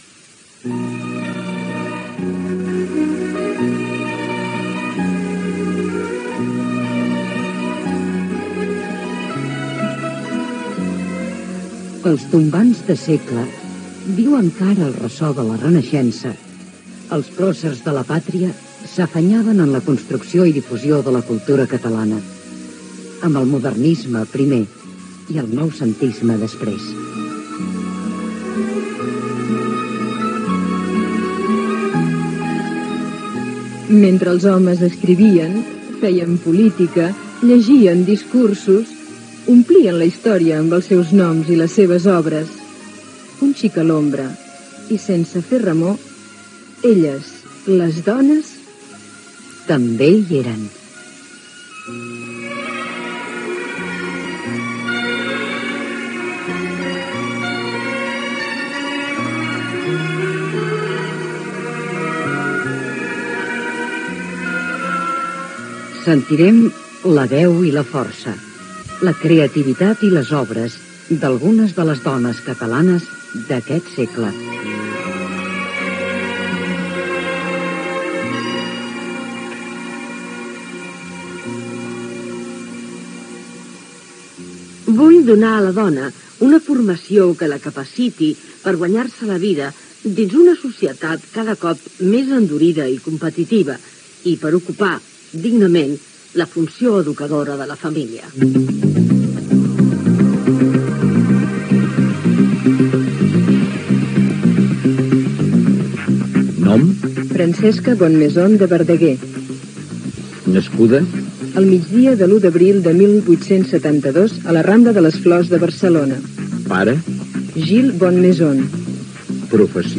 Careta del programa, presentació de l'espai dedicat a Francesca Bonnemasion i la seva obra: l'Institut de Cultura i Biblioteca Popular per a la Dona.
Divulgació